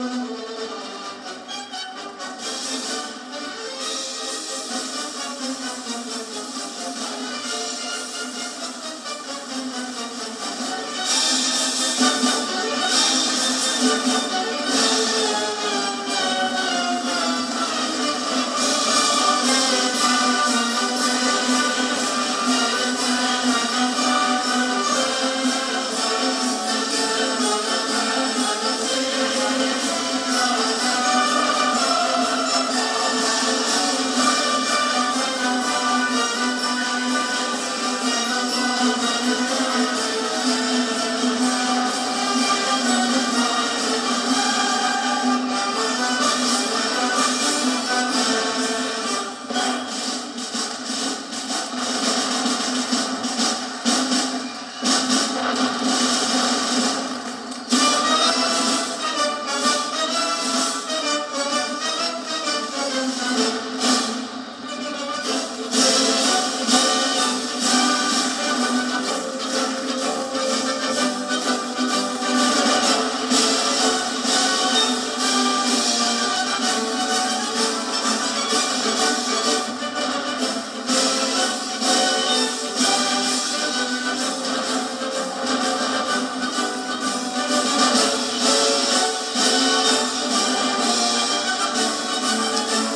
DC , PBS , Fireworks